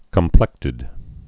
(kəm-plĕktĭd)